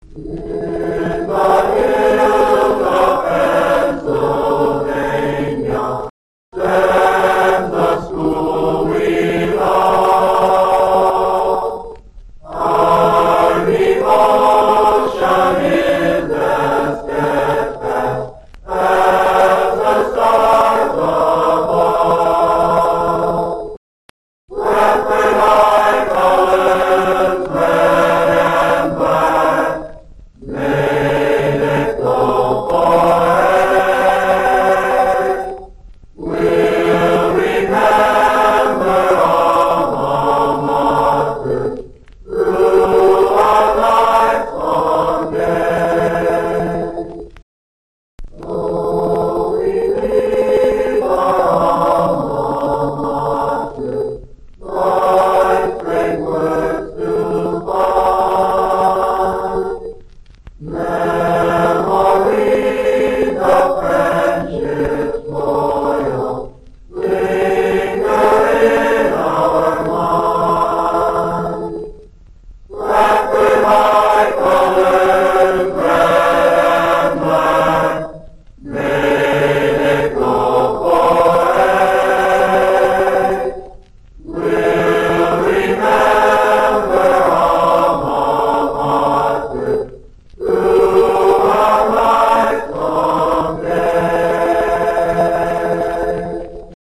to the BAHS 67/68 Choirs sing our Alma Mater